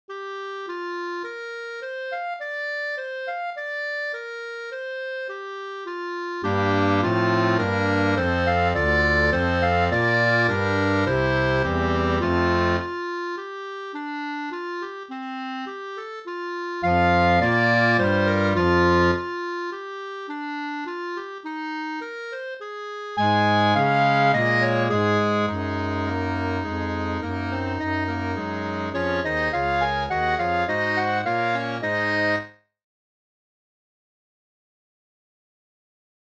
I thought, why not write this out for clarinet choir?